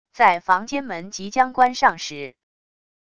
在房间门即将关上时wav音频